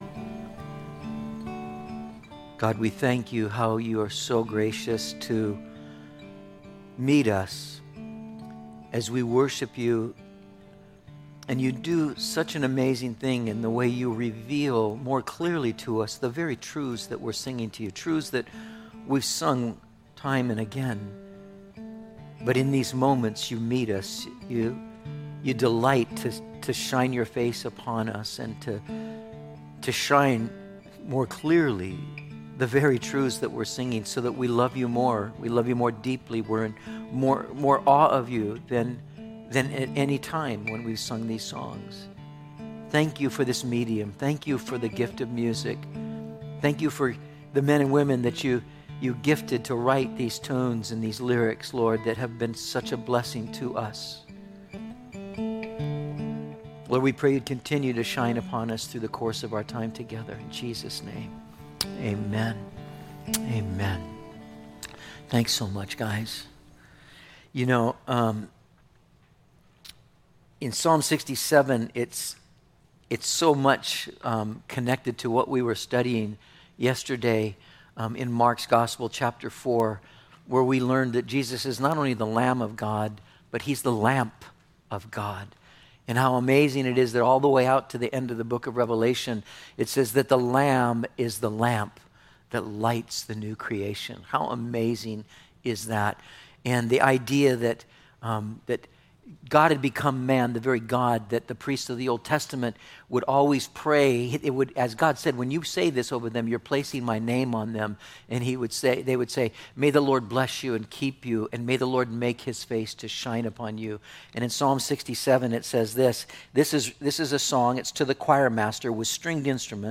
07/06/20 Imago Dei - Metro Calvary Sermons